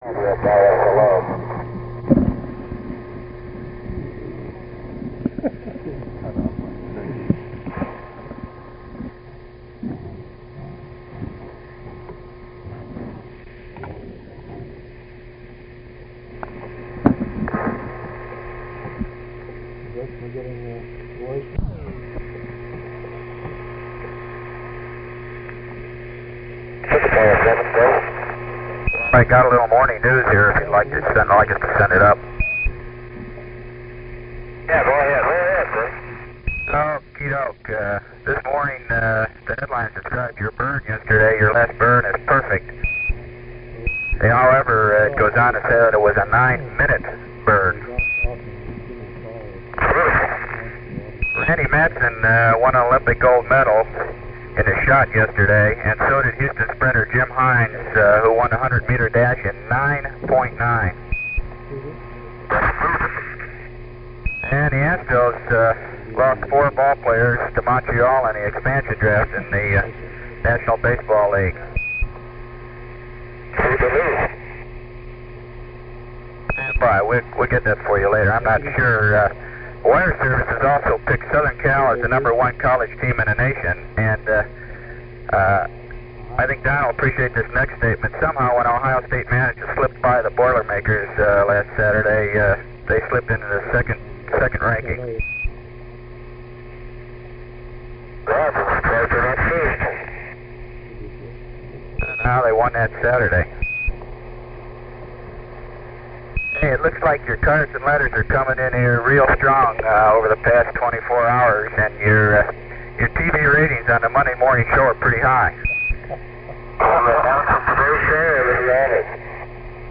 94:30 GET – 4 min 34 sec. Through Tananarive. 1.8MB mp3.
Capcom Gene Cernan reads the morning news to the crew.
Battery performance on compact cassette recorders was notorously bad.
As the recording progresses, the battery levels drop, the tape slows, and at playback the pitch goes up.
Quindar tones were used as references to correct the speed.)